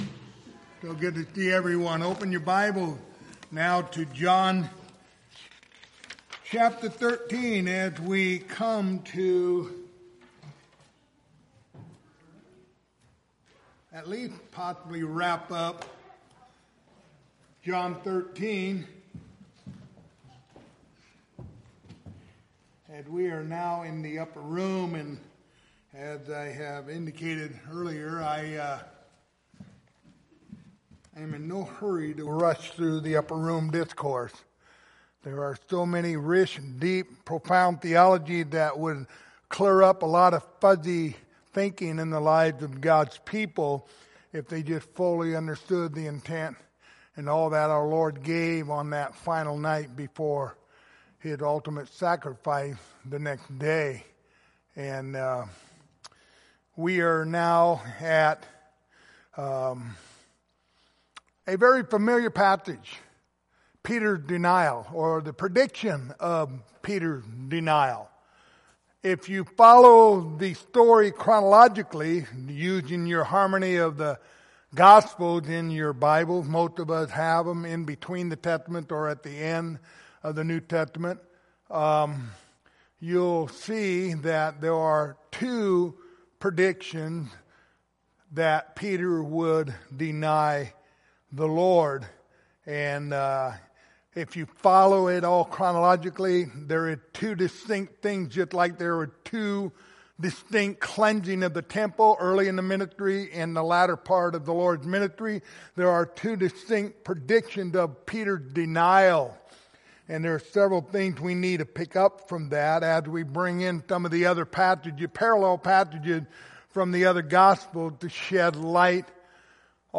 Passage: John 13:36-38 Service Type: Wednesday Evening Topics